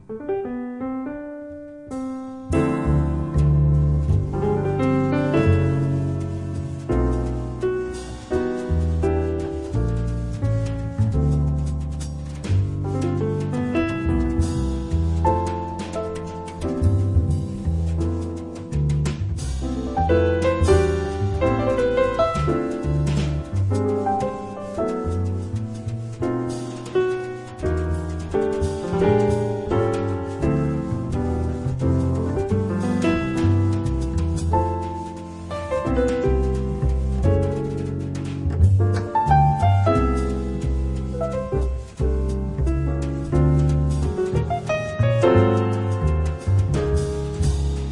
The Best In British Jazz
Recorded Red Gables Studios, London October 13th 2003